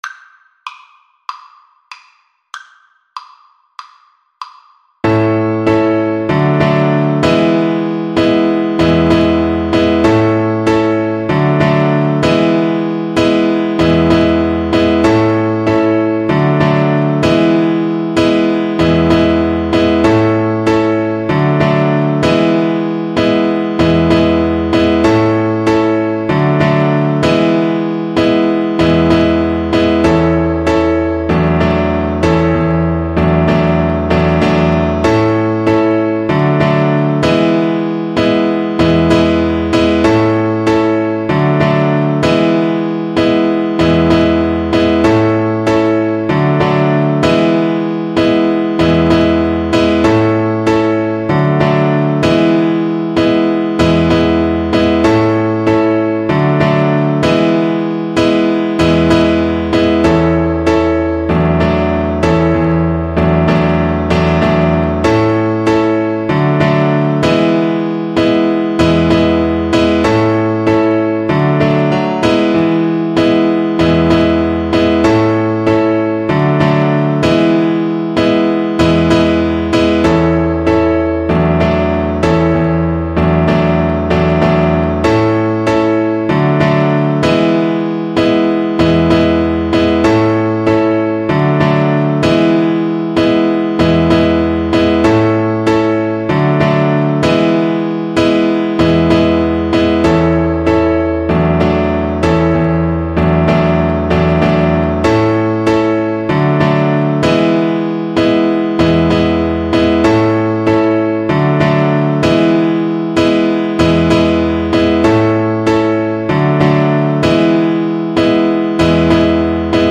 Fast and bright = c. 96
4/4 (View more 4/4 Music)
Jazz (View more Jazz Violin Music)
Rock and pop (View more Rock and pop Violin Music)